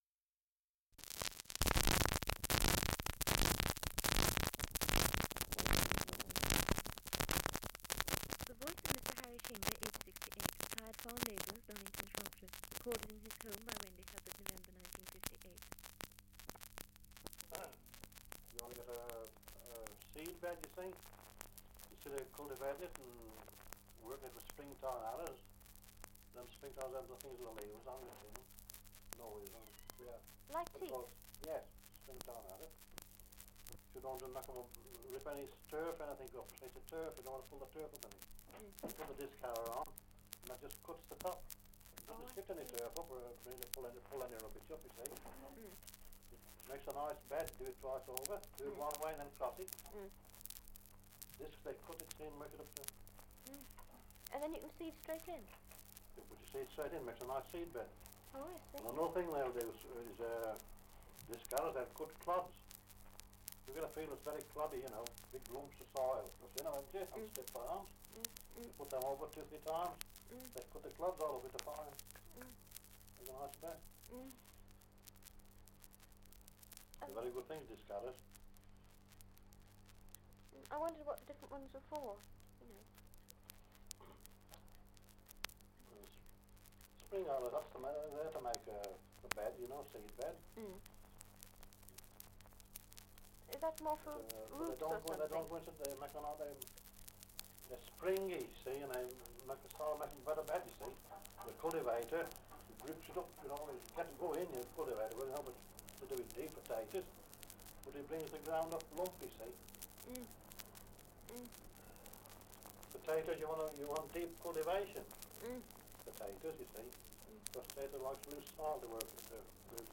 Dialect recording in Albrighton, Shropshire
78 r.p.m., cellulose nitrate on aluminium